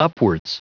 Prononciation du mot upwards en anglais (fichier audio)
Prononciation du mot : upwards